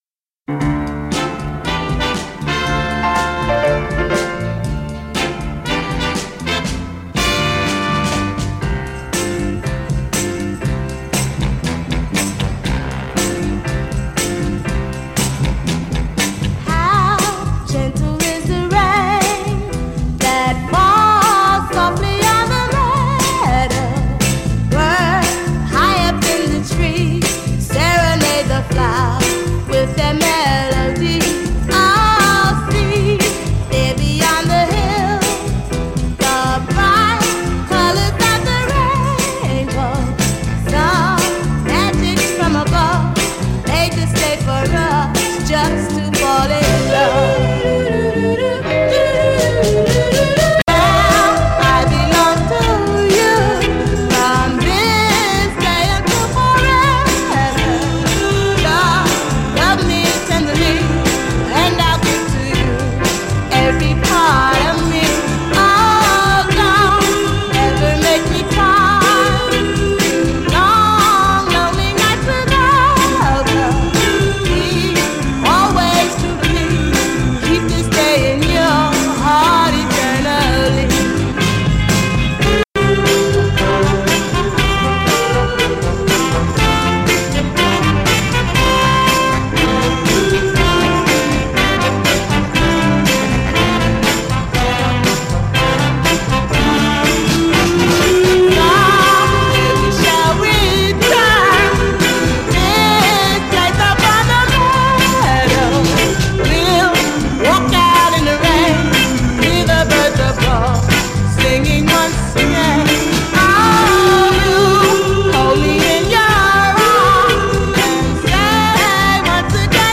Here the music has been altered to fit the lesson.